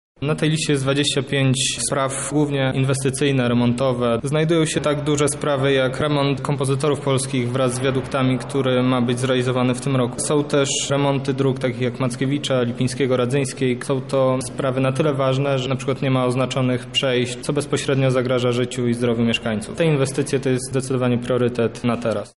O tym co w pierwszej kolejności należy wyremontować mówi Przewodniczący Rady Dzielnicy Czechów Południowy, Maciej Kowalczyk